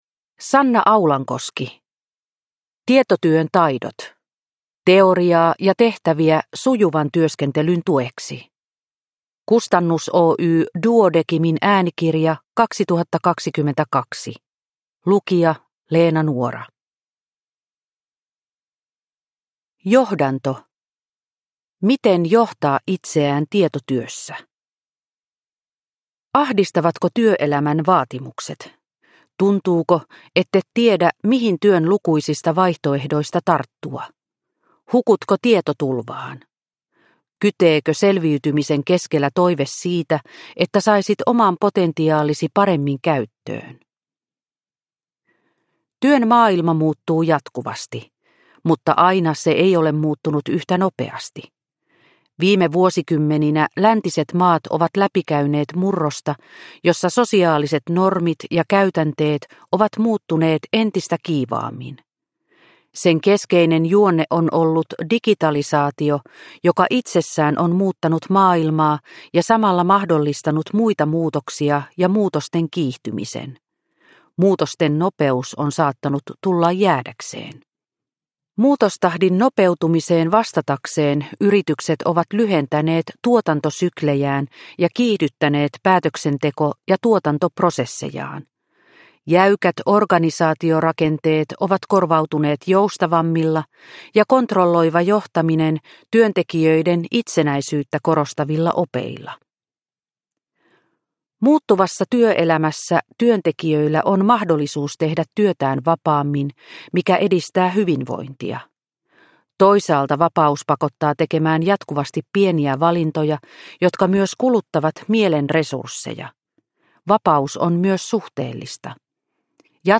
Tietotyön taidot - teoriaa ja tehtäviä sujuvan työskentelyn tueksi – Ljudbok – Laddas ner